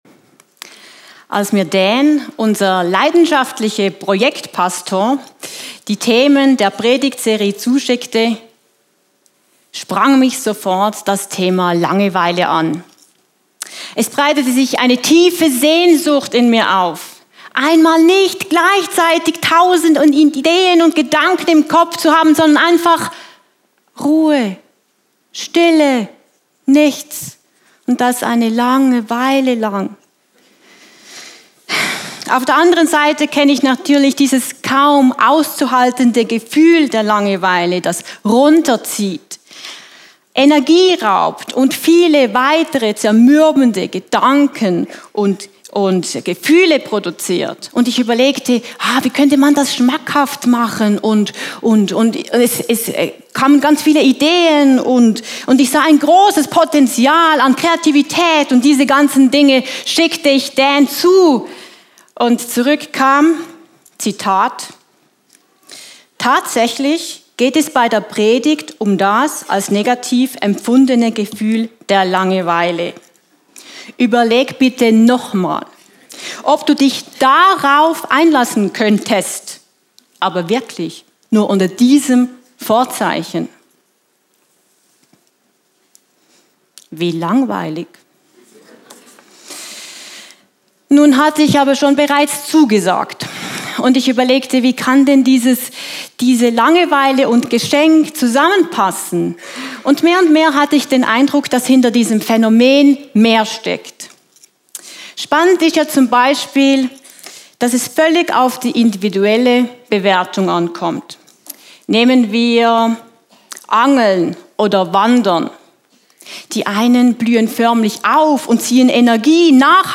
Predigt als Audio